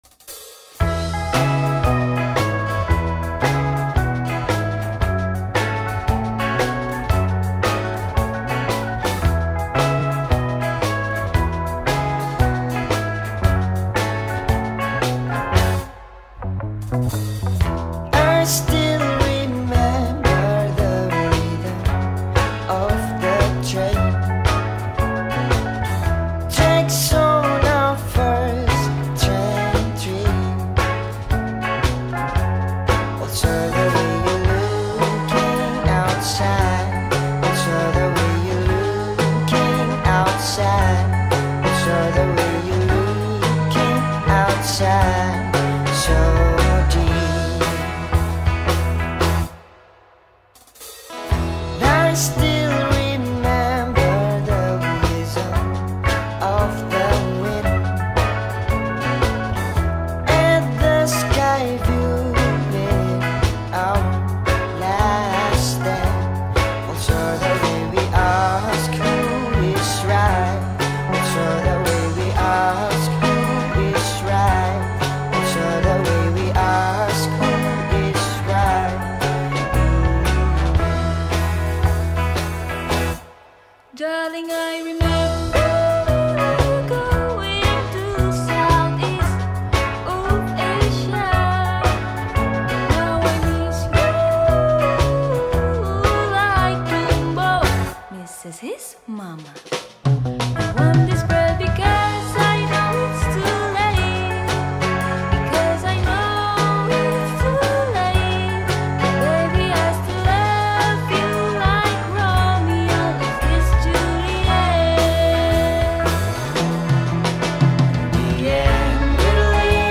Tangerang Pop
vocal
guitar
bass
drum